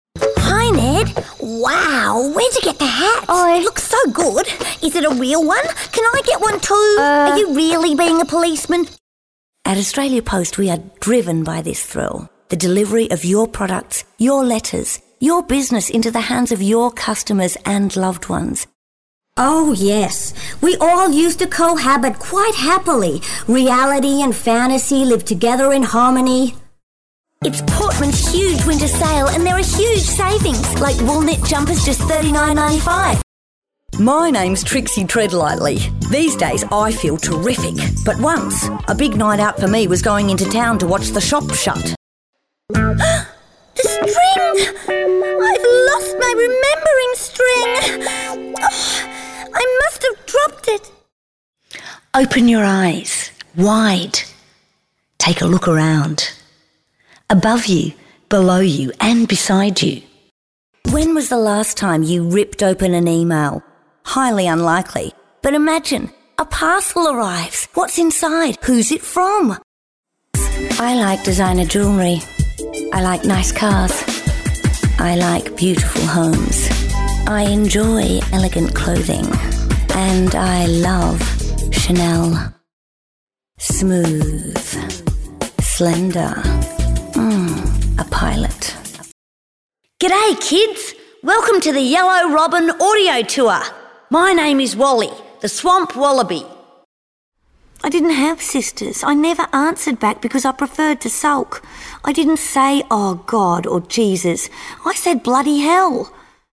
Warm, fun, engaging and natural Lucinda has worked as voice artist extensively for over 30 years both here and in the UK in various Animations including “Koala Brothers”, BBC Radio Drama “Our Father Who Art In A Tree”, ”We Need to Talk About Kevin”
Lucinda Cowden Voice Reel
Middle Aged